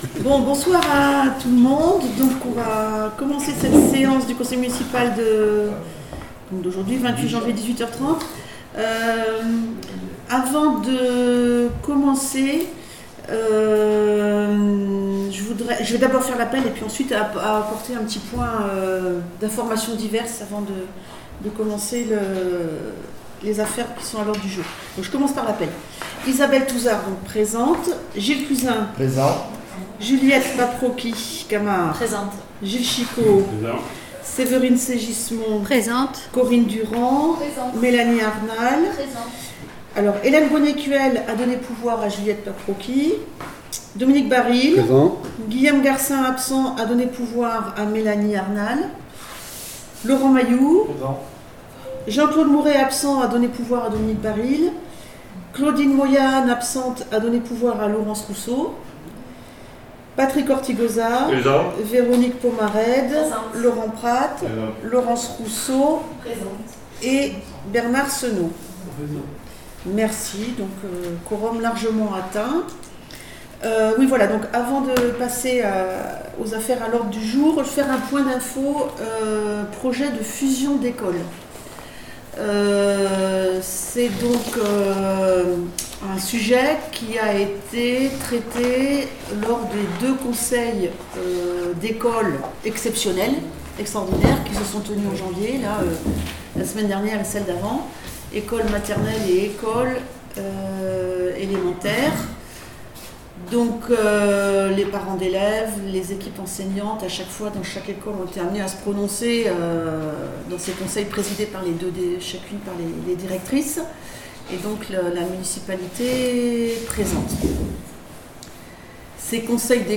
Séance du conseil municipal du 25 janvier 2024